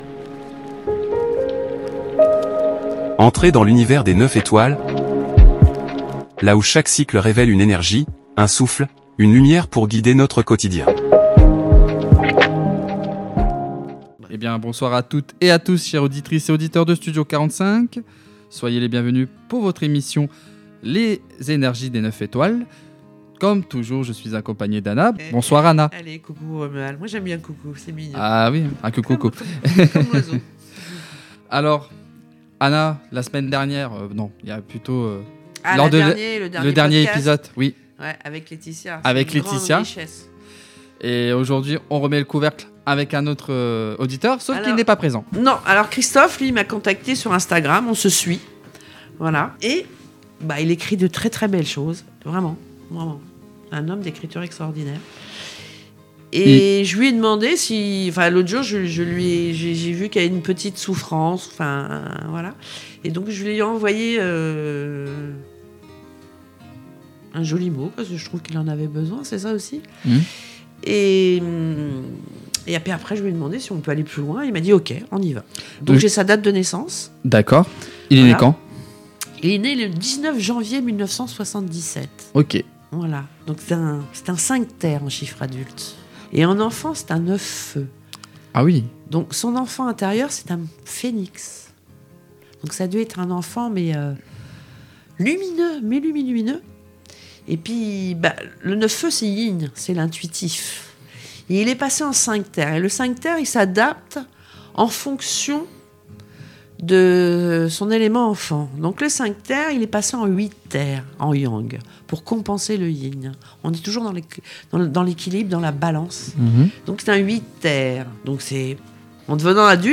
Dans cet épisode exceptionnel de Les Énergies des 9 Étoiles, nous invitons vous, les auditeurs, à prendre la parole !
Des échanges spontanés et authentiques entre auditeurs Une plongée dans les énergies du quotidien — vécues, ressenties, questionnées